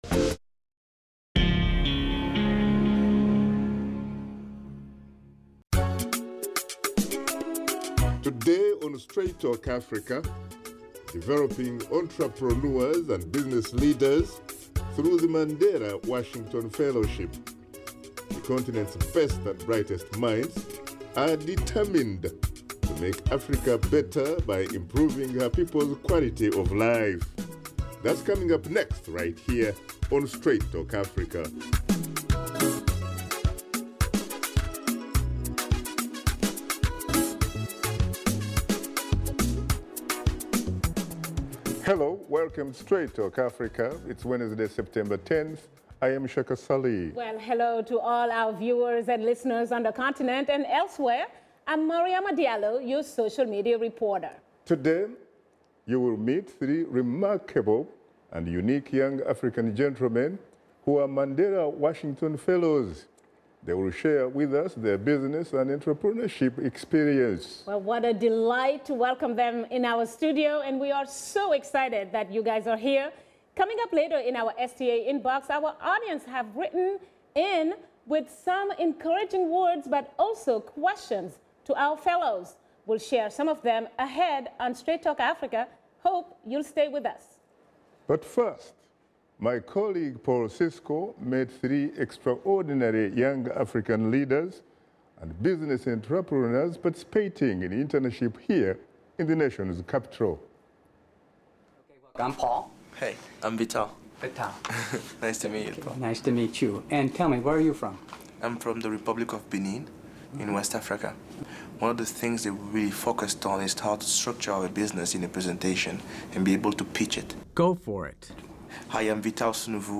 Washington Studio Guests